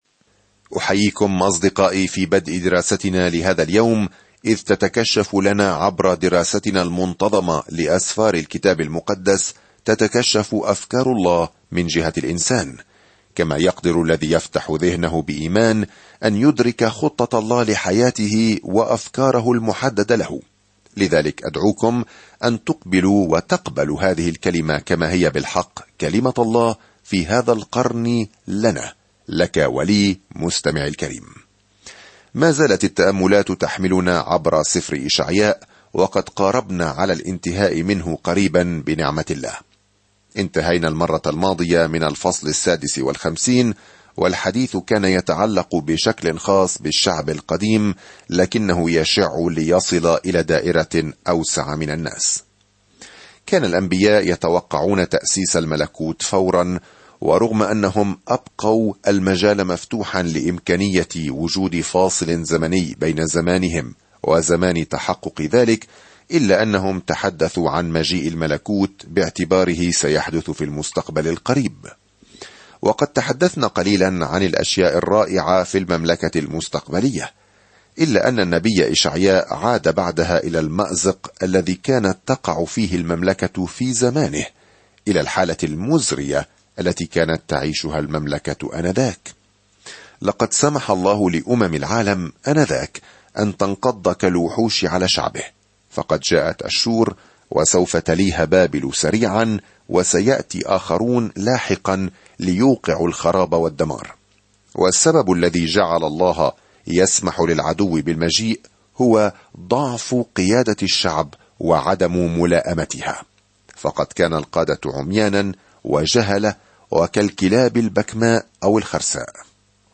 الكلمة إِشَعْيَاءَ 6:57-21 إِشَعْيَاءَ 1:58-3 يوم 42 ابدأ هذه الخطة يوم 44 عن هذه الخطة ويصف إشعياء، المسمى "الإنجيل الخامس"، ملكًا وخادمًا قادمًا "سيحمل خطايا كثيرين" في وقت مظلم عندما يسيطر الأعداء السياسيون على يهوذا. سافر يوميًا عبر إشعياء وأنت تستمع إلى الدراسة الصوتية وتقرأ آيات مختارة من كلمة الله.